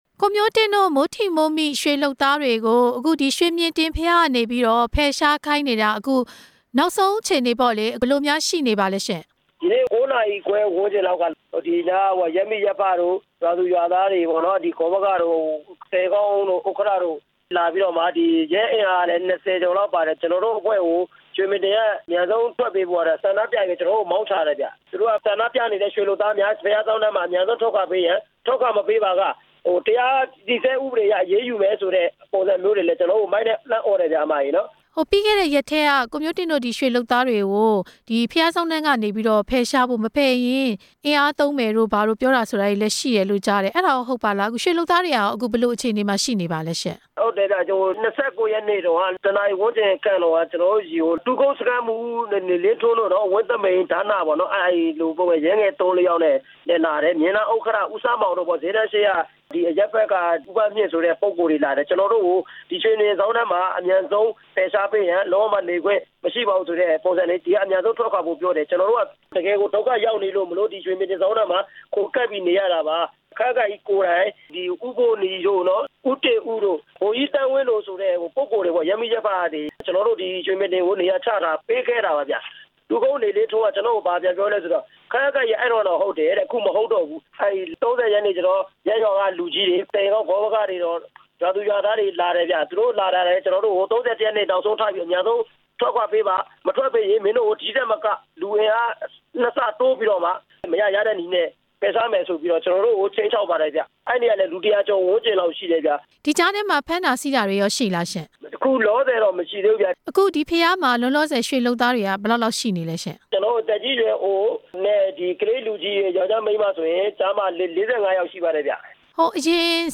ဆက်သွယ်မေးမြန်းခဲ့ပါတယ်။